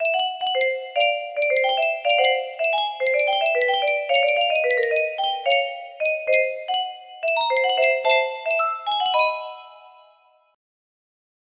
randomness music box melody